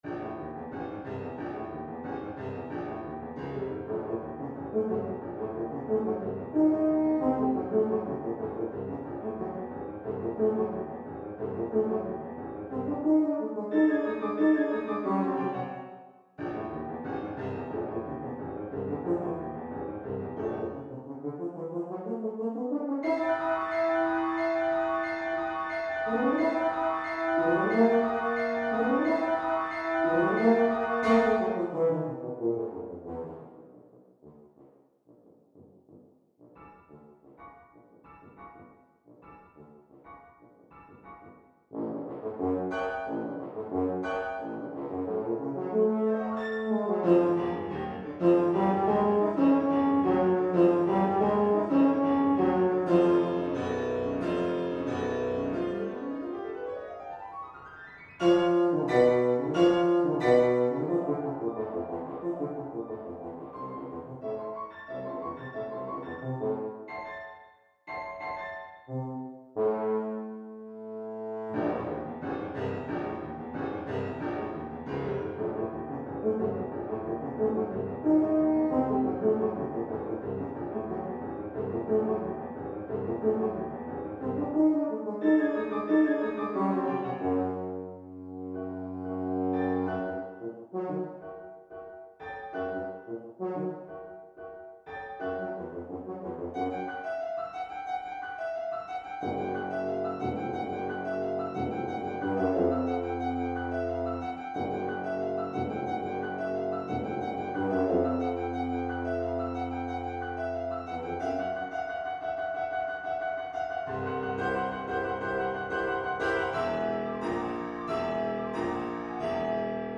Genre: Duet for Tuba & Piano
Tuba
Piano